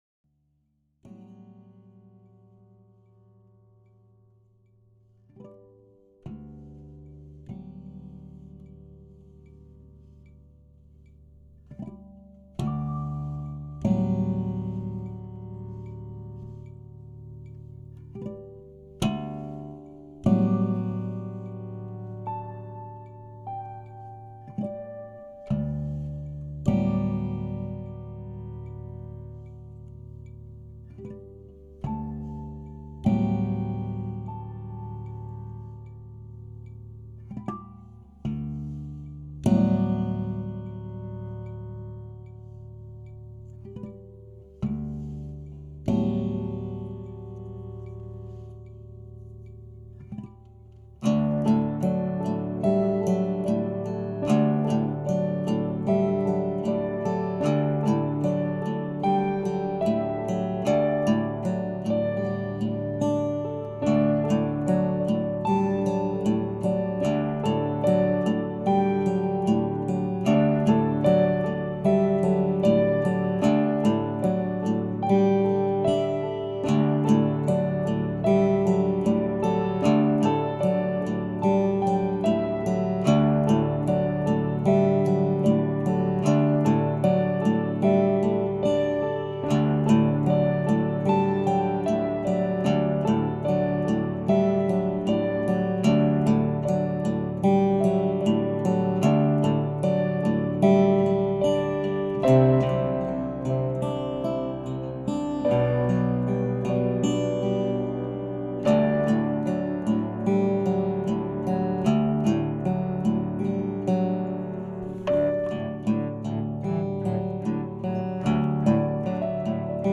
Includes new age, soul, acoustic guitar & rock.